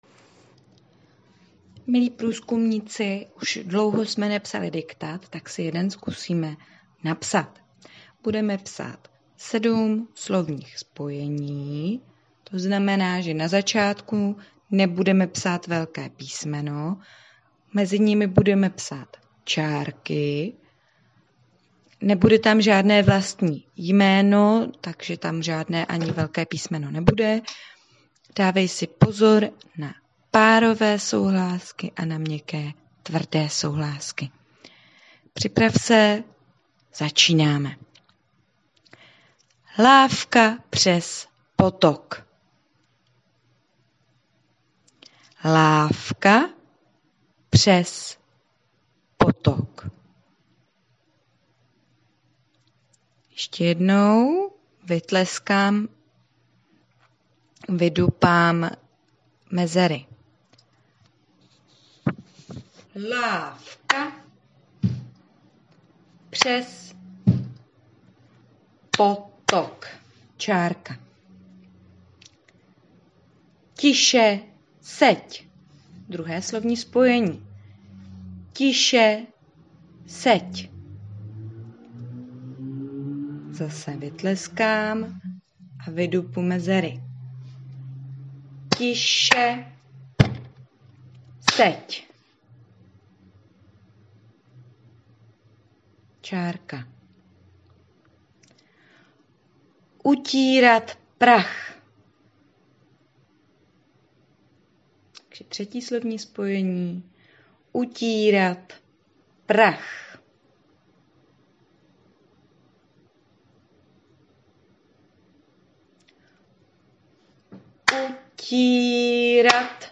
Diktát.mp3